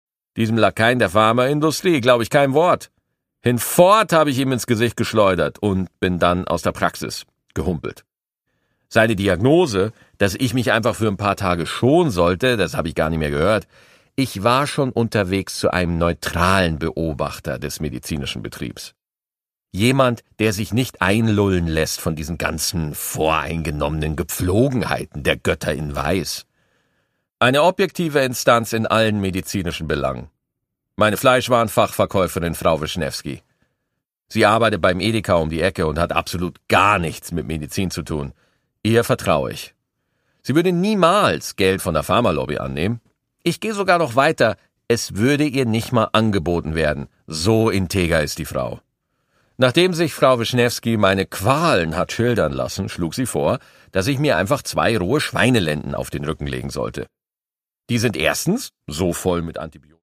Produkttyp: Hörbuch-Download
Gelesen von: Maxi Gstettenbauer